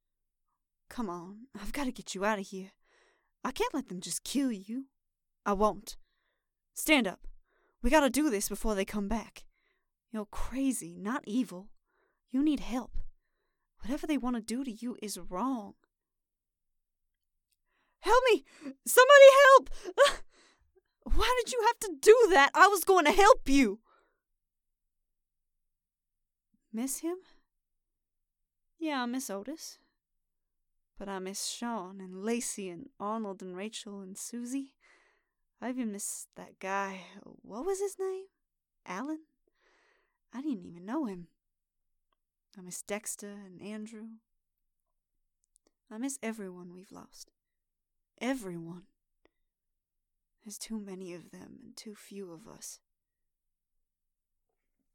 Voice Actor
She has a southern accent.